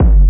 Index of /m8-backup/M8/Samples/breaks/breakcore/earthquake kicks 1
phat kick.wav